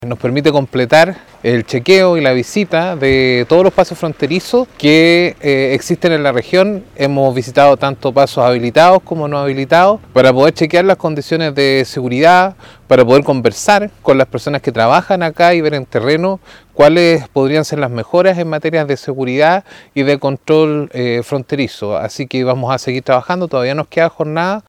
La autoridad dijo que anteriormente se visitó Pino Hachado e Icalma, indicando que se busca mejorar en seguridad y control fronterizo.